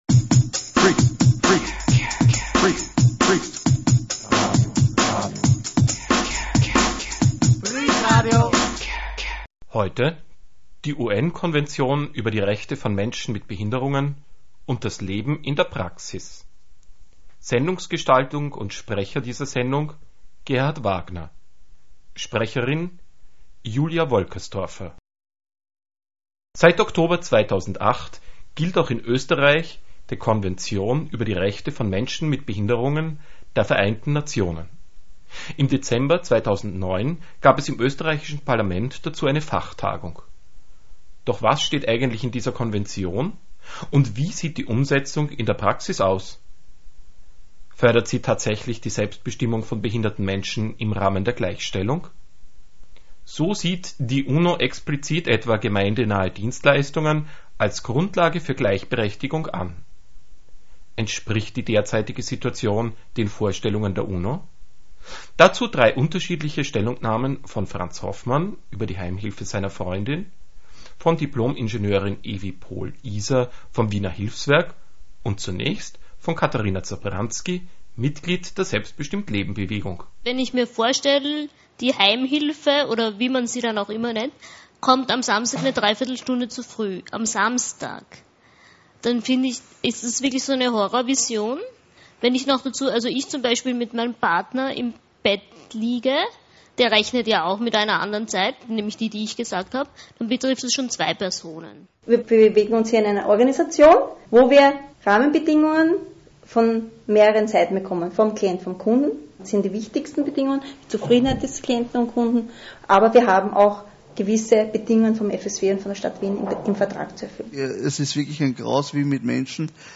In dieser Sendung werden nicht nur die Inhalte der UNO-Konvention über die Rechte von Menschen mit Behinderungen angesprochen. Sie werden auch mit der Situation im Alltag Wiens in Beziehung gesetzt: Zufriedene und unzufriedene Kunden kommen ebenso zu Wort wie Vertreter von Einrichtungen.